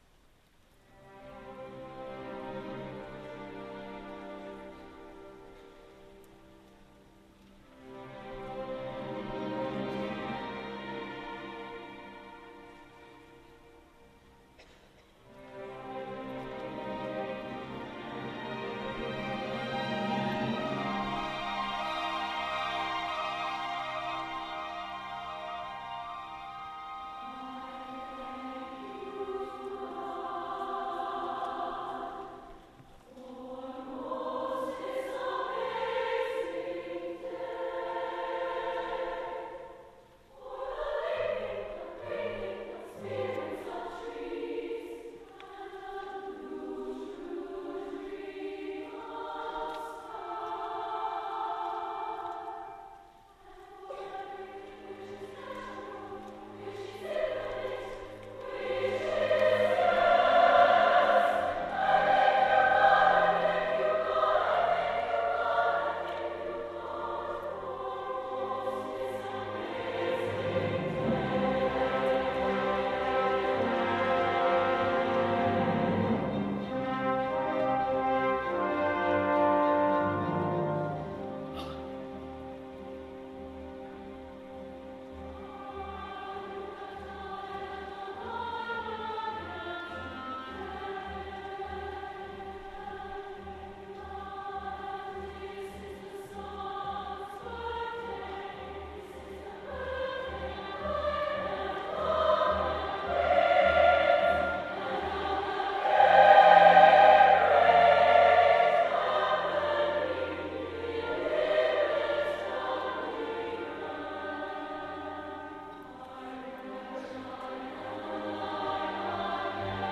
an MP3 file of this work (SSA and orchestra).